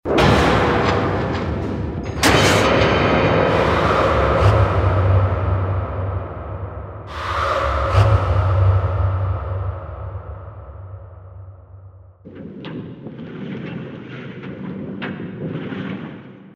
pendulum.mp3